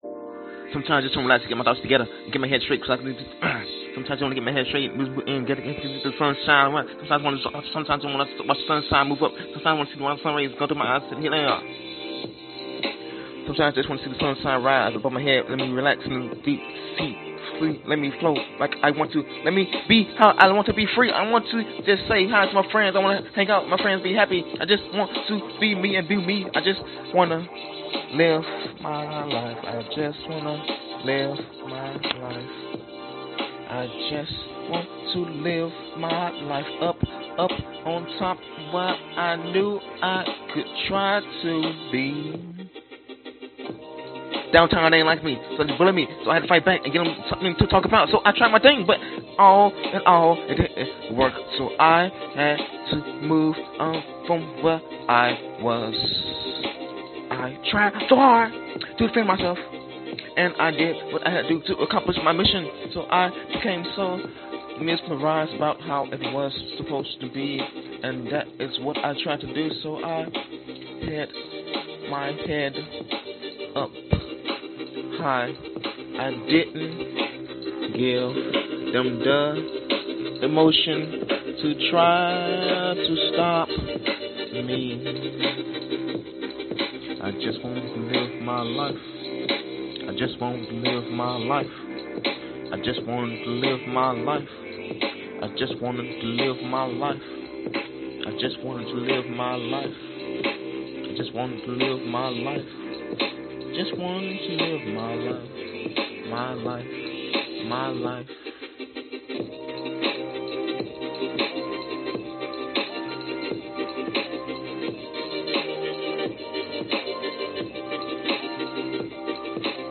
windchimes1
描述：Wanted to make a beautiful newage synth pad with spacious windchimes. Recorded with a Sony IC recorder, cleaned up using Edison in Fl Studio.
标签： windchime relaxing newage sonyicrecorder
声道立体声